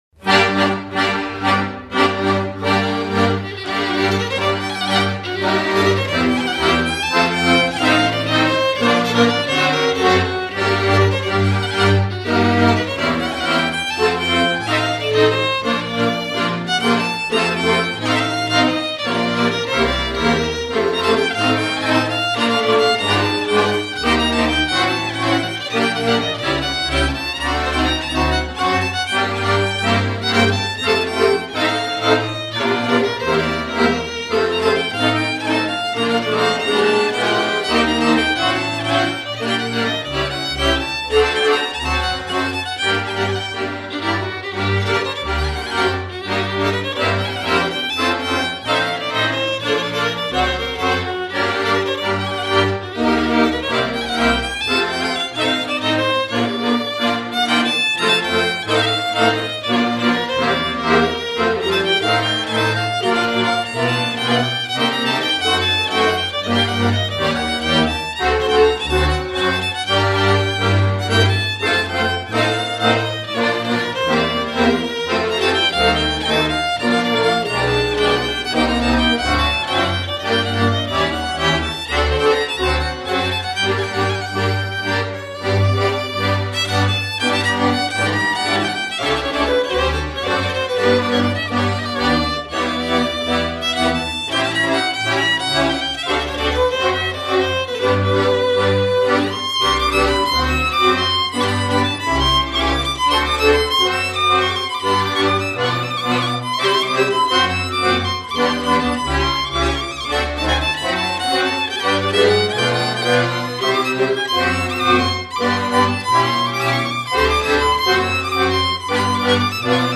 vioară